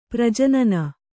♪ prajanana